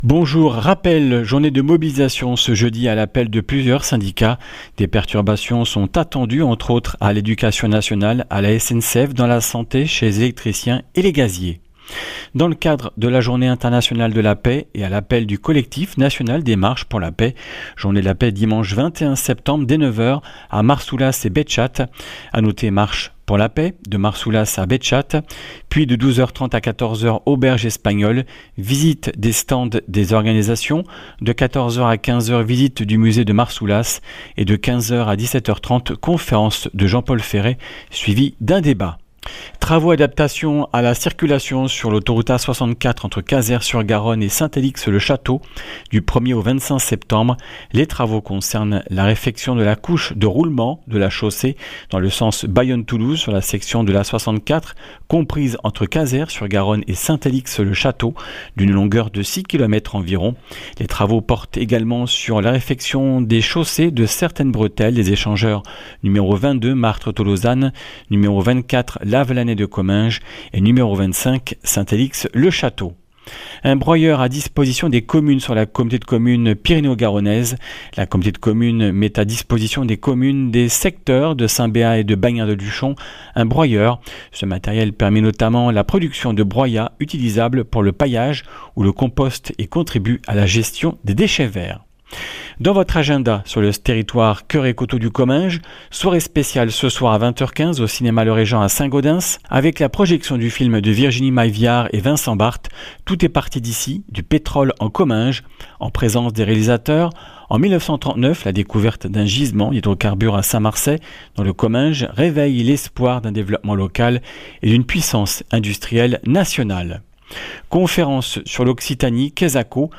Journaliste